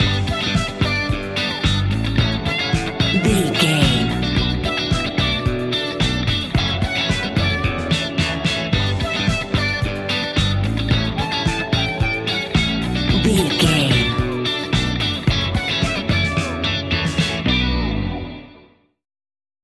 Ionian/Major
D♭
house
electro dance
synths
techno
trance